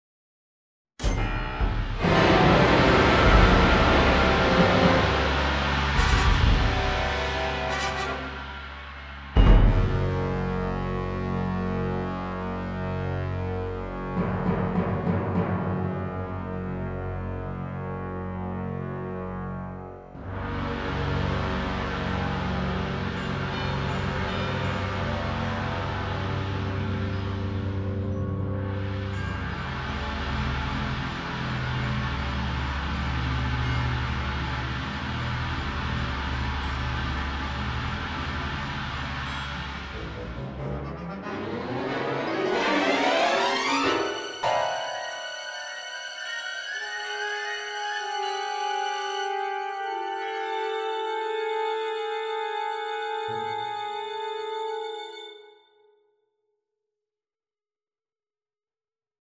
for large orchestra
Percussion (three players)
Piano, celesta, harp